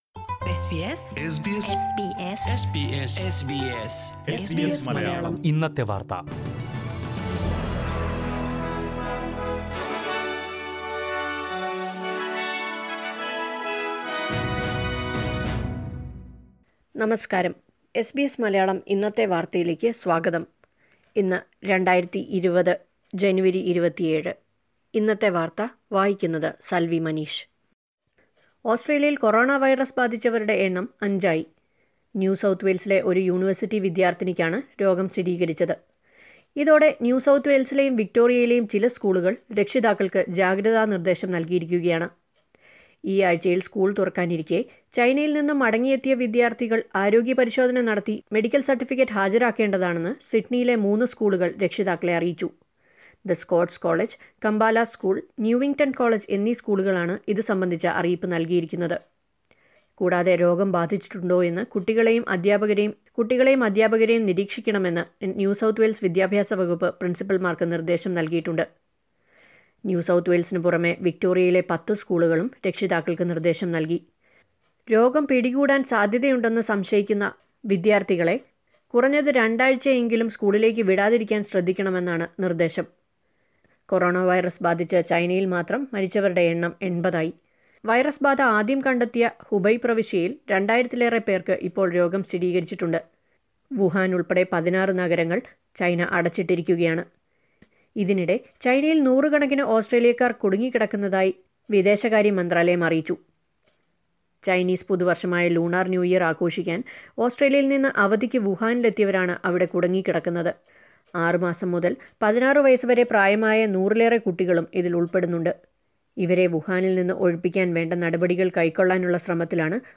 2020 ജനുവരി 27ലെ ഓസ്ട്രേലിയയിലെ ഏറ്റവും പ്രധാന വാര്‍ത്തകള്‍ കേള്‍ക്കാം...